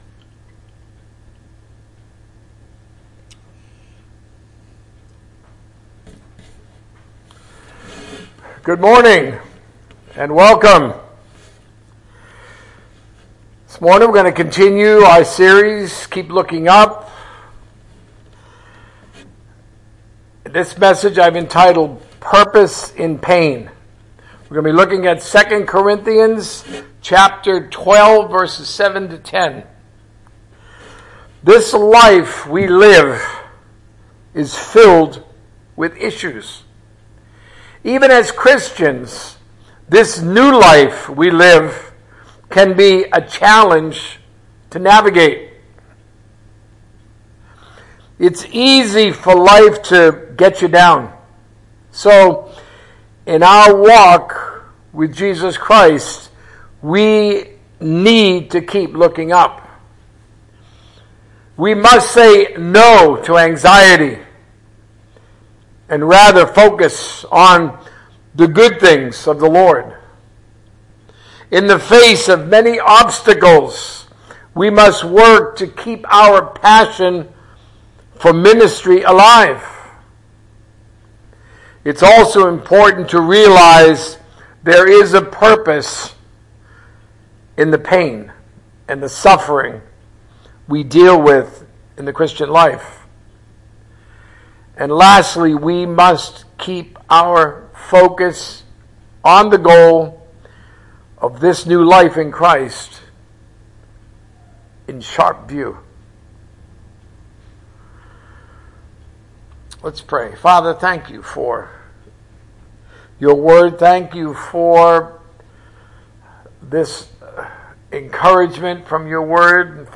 A message from the series "Keep Looking Up."